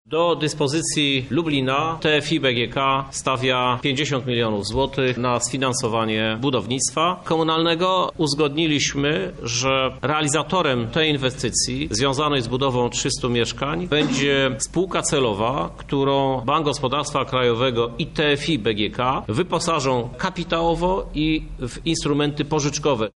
O szczegółach mówi prezydent Lublina Krzysztof Żuk: